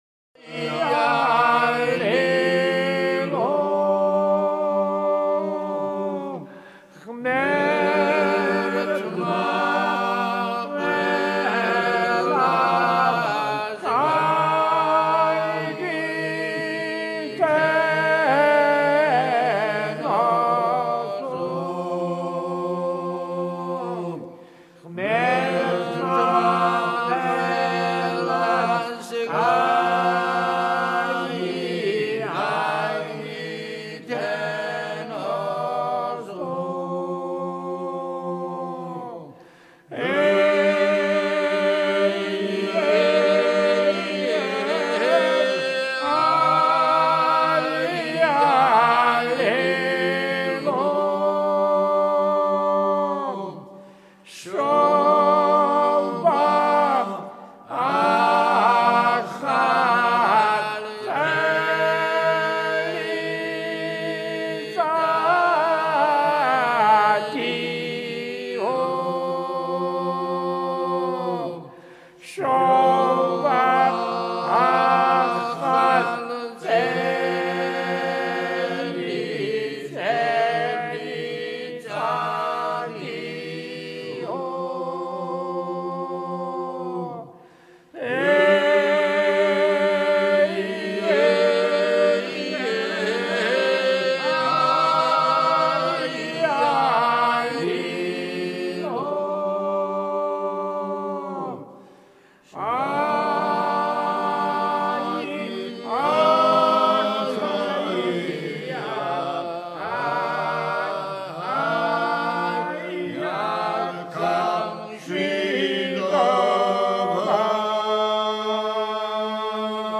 Georgian Folklore